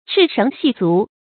chì shéng jì zú
赤绳系足发音
成语注音 ㄔㄧˋ ㄕㄥˊ ㄒㄧˋ ㄗㄨˊ